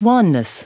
Prononciation du mot wanness en anglais (fichier audio)
Prononciation du mot : wanness